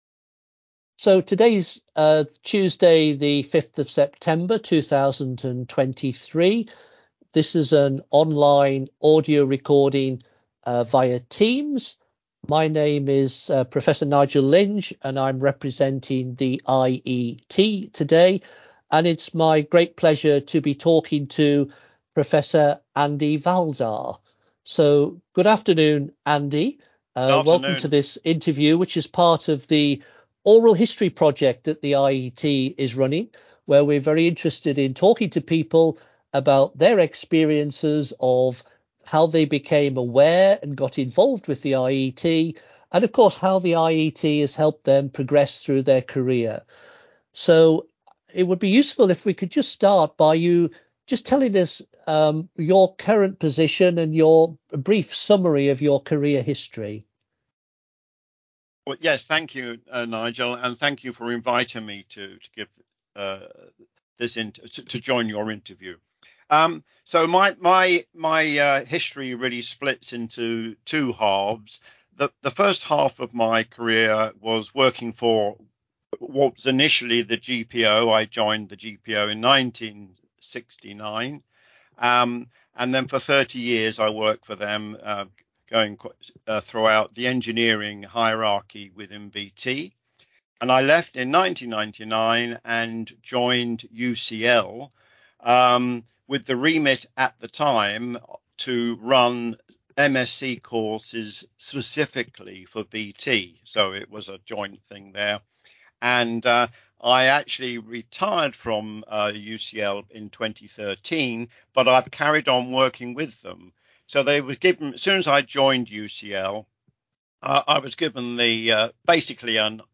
via Teams.